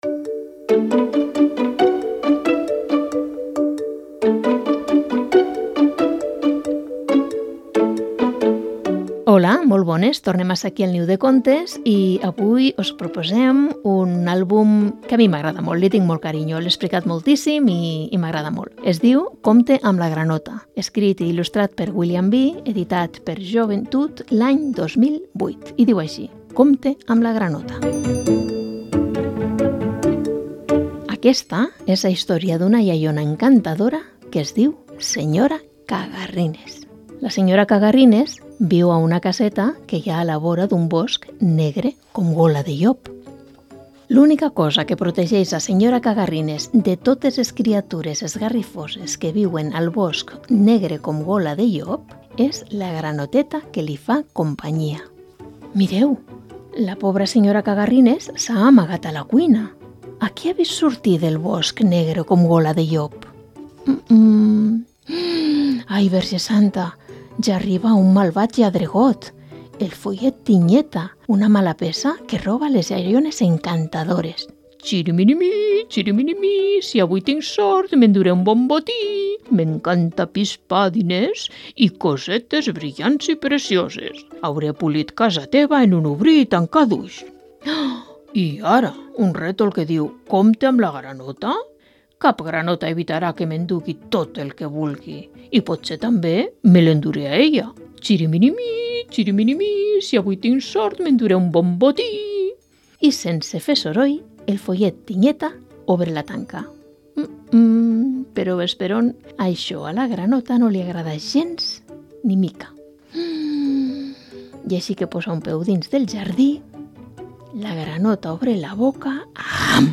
Programa de lectura de contes